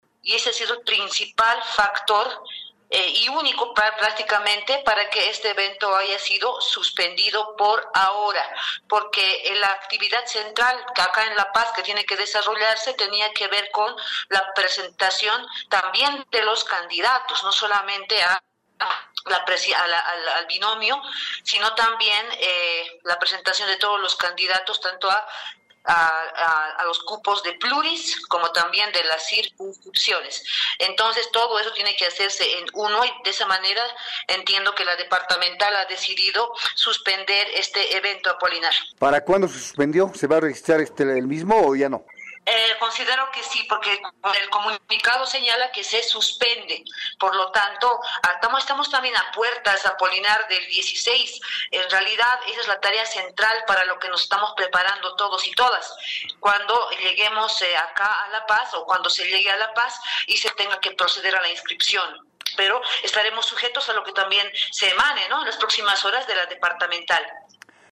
La concejala evista Wilma Alanoca explicó a Radio Fides que la suspensión del acto en El Alto responde a una “coincidencia de horarios” con una actividad previamente agendada en el municipio de Chimoré, Cochabamba, lo que obligó a Evo Morales a priorizar su presencia en ese evento.